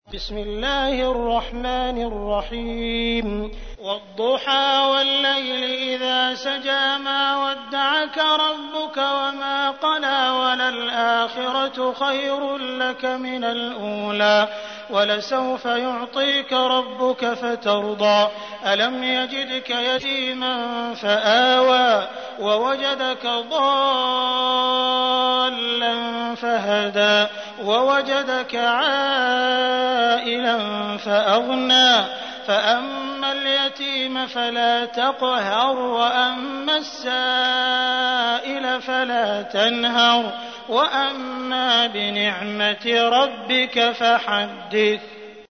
تحميل : 93. سورة الضحى / القارئ عبد الرحمن السديس / القرآن الكريم / موقع يا حسين